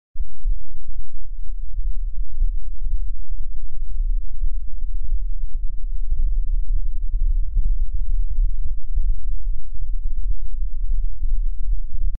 Купил новый микрофон к2 и вот такие странные дела...
Потом обратил внимание на гул ниже 50 гц.
Так то при записи в преампе обрезной стоит случайно его выключил и обнаружил этот гул на записи...
Вложения Шум.mp3 Шум.mp3 190,2 KB · Просмотры: 691 Безымянный.jpg 203,5 KB · Просмотры: 246